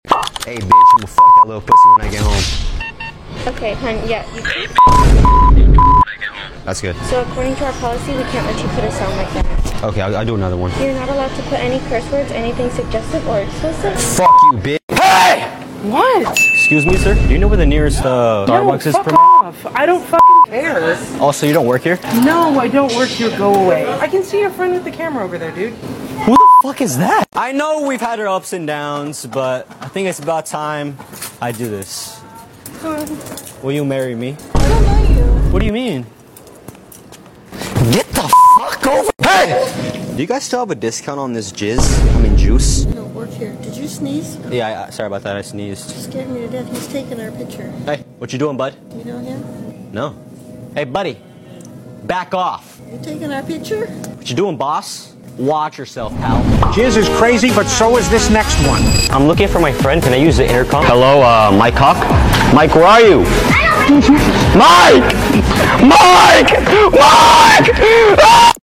This short is packed with fun edits, commentary, and reactions — not your typical ranking video.